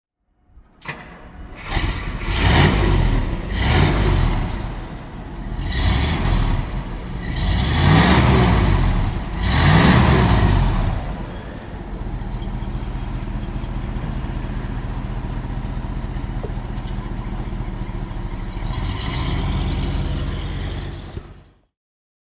Motorsounds und Tonaufnahmen zu Rolls-Royce Fahrzeugen (zufällige Auswahl)
Rolls-Royce Silver Cloud III (1963) - Starten und Leerlauf